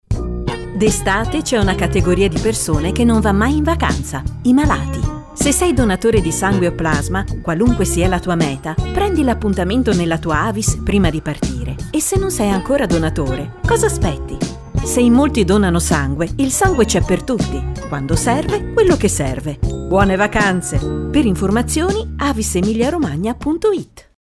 Una consuetudine consolidata ed efficace a cui quest’anno si affianca una campagna radiofonica (potete ascoltarla qui sotto) sulle principali emittenti locali per ricordare anche a chi non è donatore che l’estate è un buon momento anche per prenotare la visita di idoneità, così da poter essere donatori a tutti gli effetti con la ripresa di gran parte delle attività, a settembre.